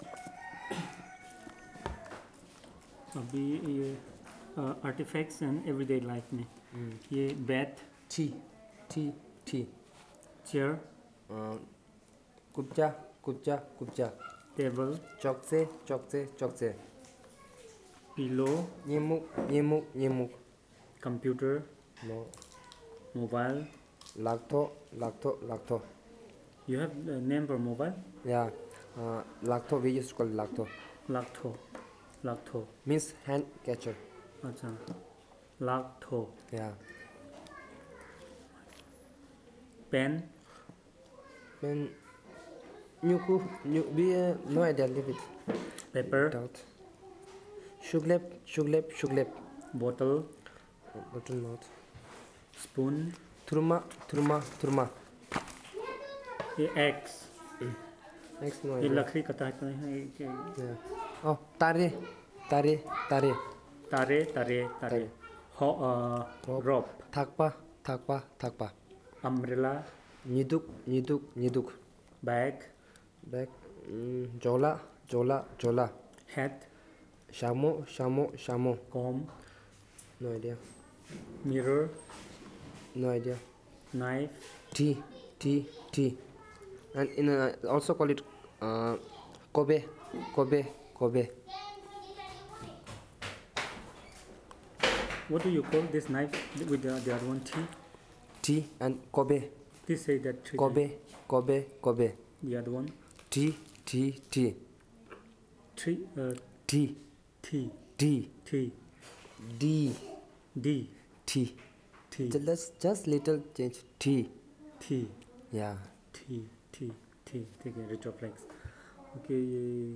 NotesThis is an elicitation of words about artifacts and household items.